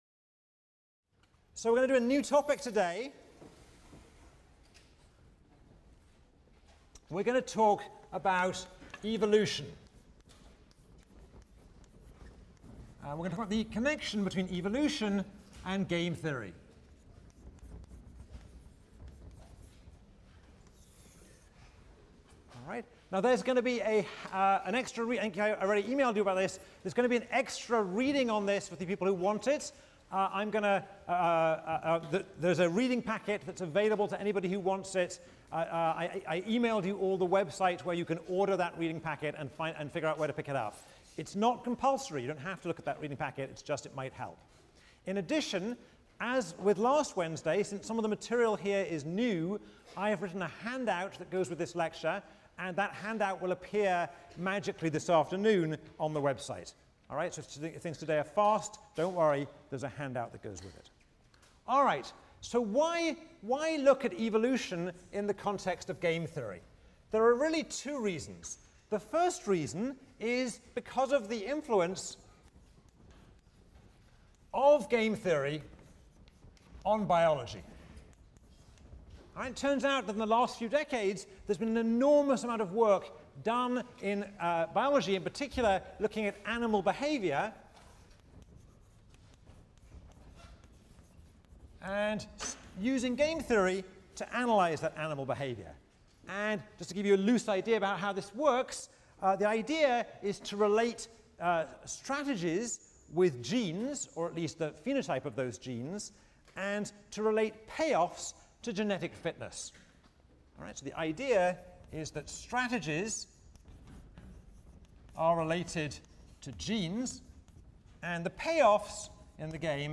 ECON 159 - Lecture 11 - Evolutionary Stability: Cooperation, Mutation, and Equilibrium | Open Yale Courses